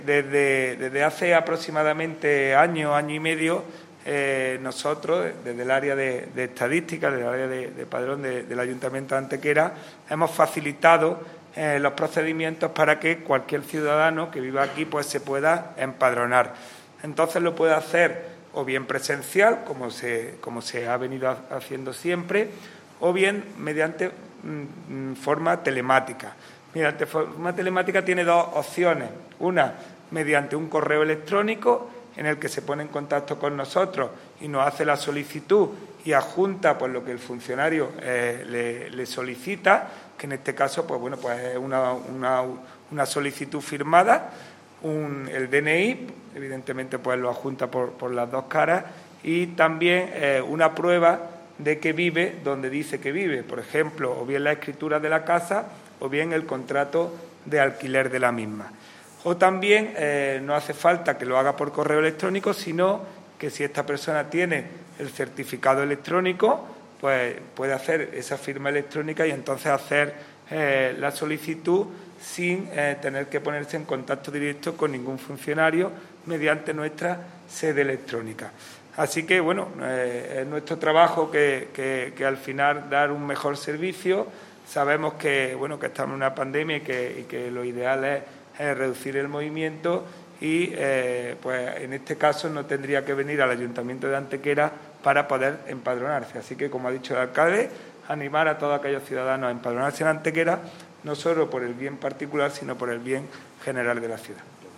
El alcalde de Antequera, Manolo Barón, y el teniente de alcalde delegado de Presidencia y Régimen Interior, Juan Rosas, han informado en rueda de prensa sobre los datos estadísticos del padrón municipal de habitantes de la década que acaba de concluir.
Cortes de voz